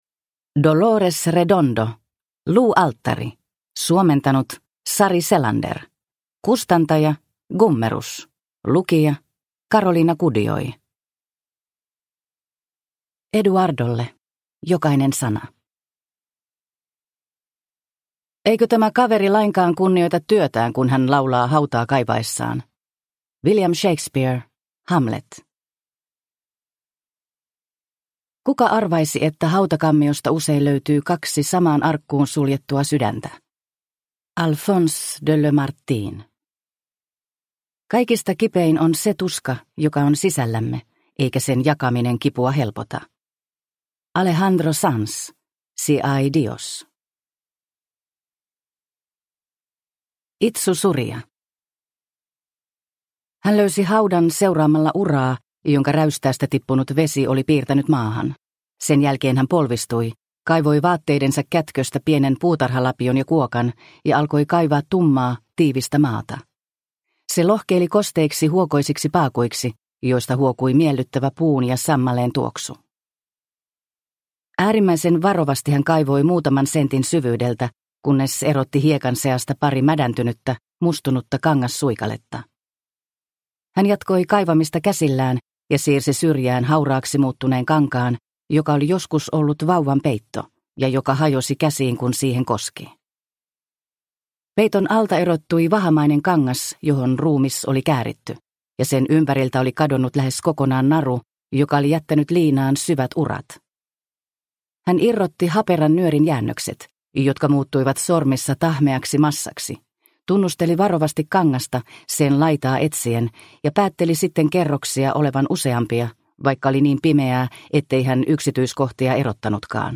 Luualttari – Ljudbok – Laddas ner